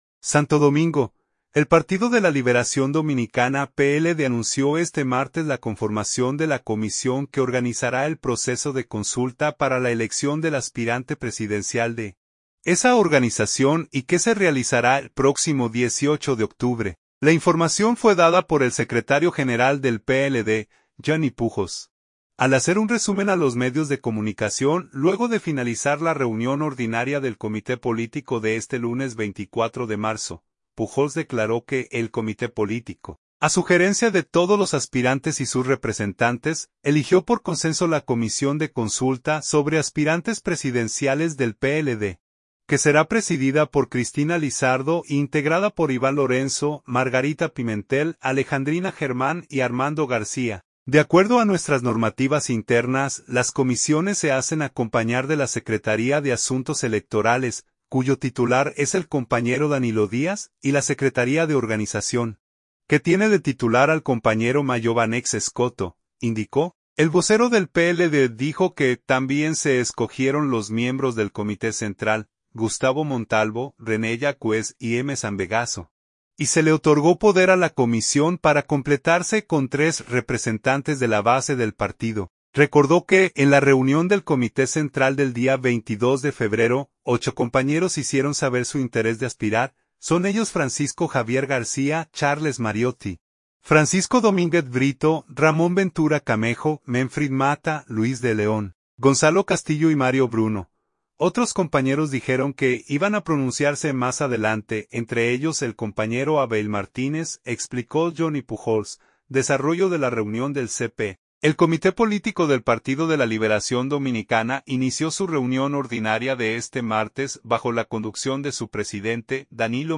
La información fue dada por el secretario general del PLD, Johnny Pujos, al ofrecer un resumen a los medios de comunicación luego de finalizar la reunión ordinaria del Comité Político de este lunes 24 de marzo.